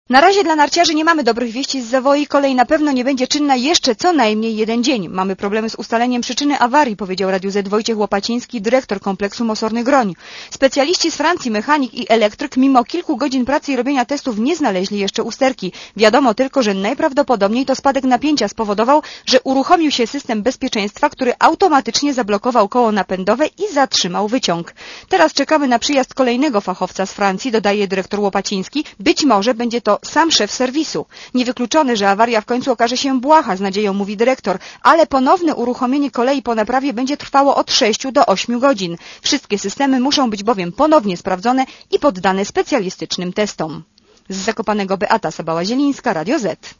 Posłuchaj relacji reporterki Radia Zet (181 KB)